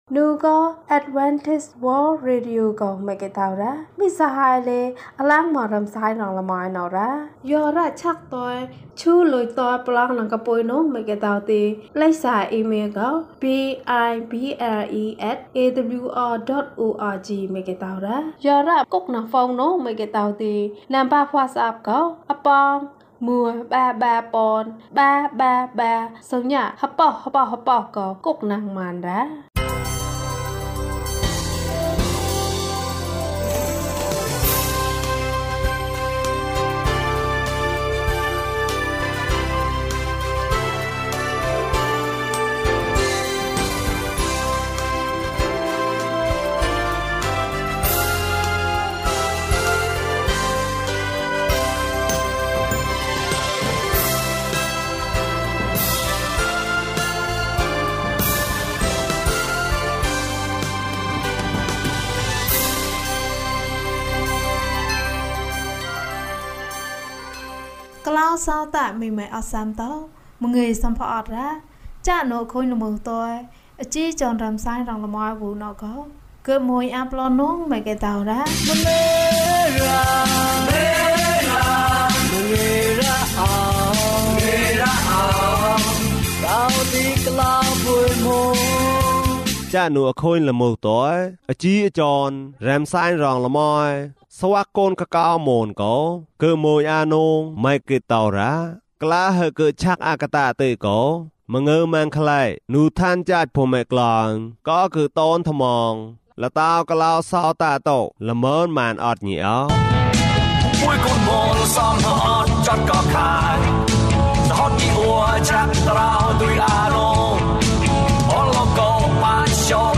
သခင်ယေရှု။ ကျန်းမာခြင်းအကြောင်းအရာ။ ဓမ္မသီချင်း။ တရားဒေသနာ။